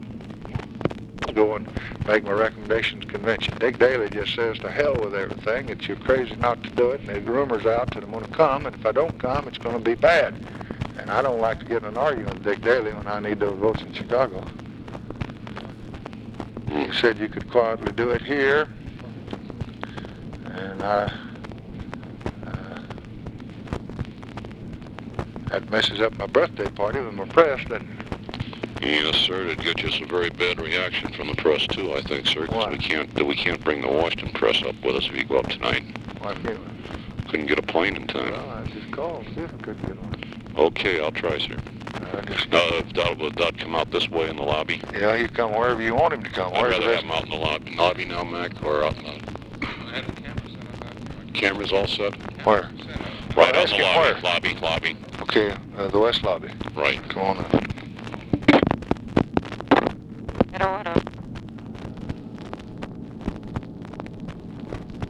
Conversation with GEORGE REEDY, August 26, 1964
Secret White House Tapes